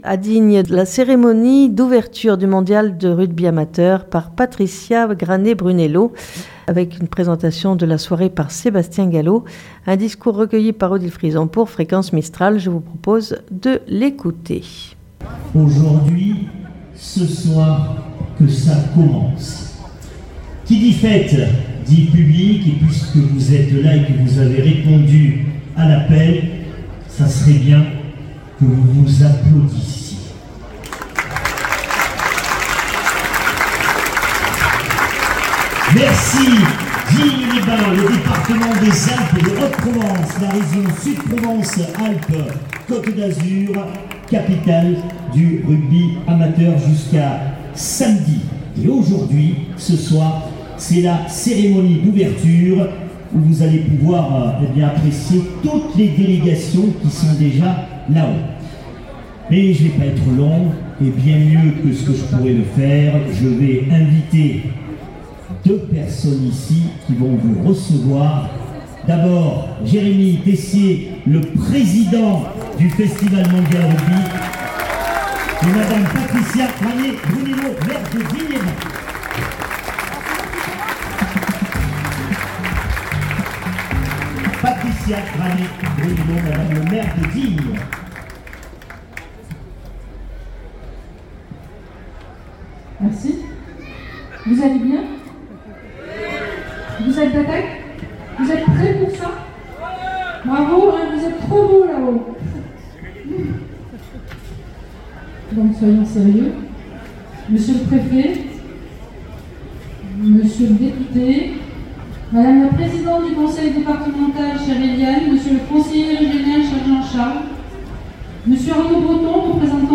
A Digne les bains, cérémonie d'ouverture du Mondial de Rugby amateur par Patricia Granet-Brunello
Patricia Granet-Brunello maire de Digne les Bains Discours